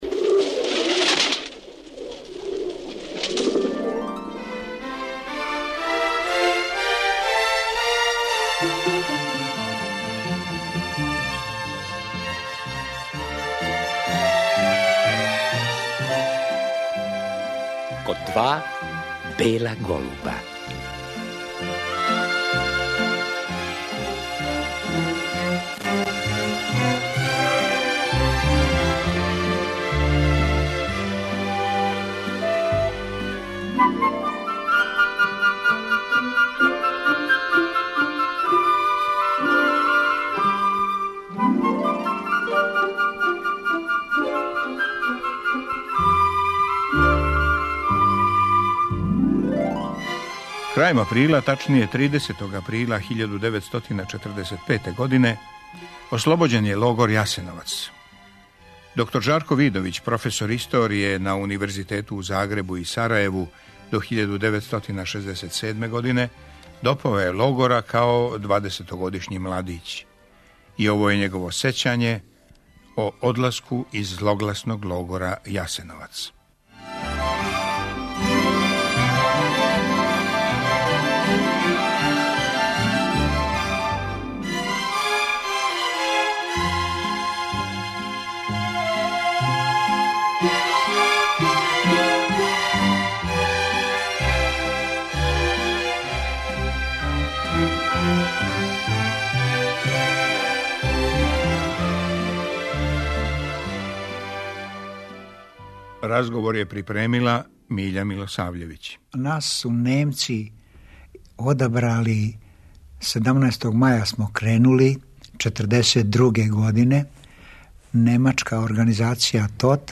Чућемо његова сећања на пробој из логора који су, по његовим речима, извеле занатлије.